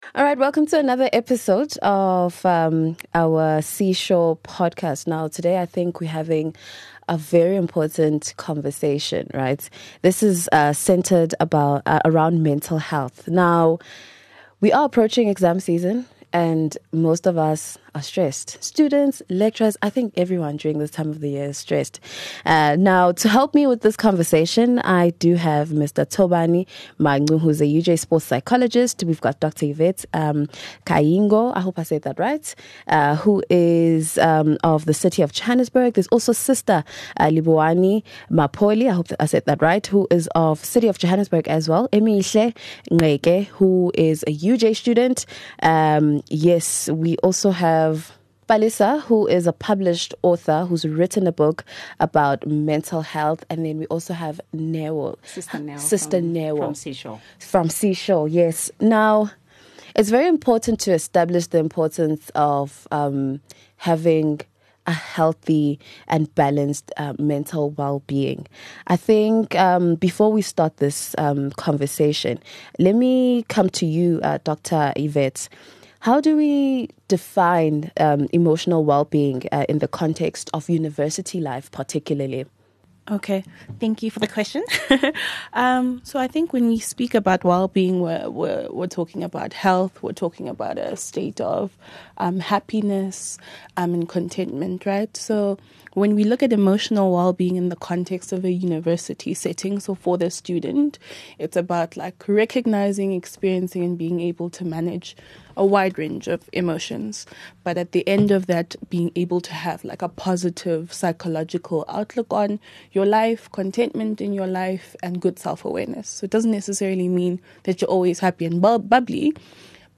In this candid discussion, mental health professionals and a current university of Johannesburg student dive deep into the emotional highs and lows of academic life. From managing test anxiety and panic attacks to setting boundaries and navigating comparison, we explore real challenges and practical strategies for maintaining balance.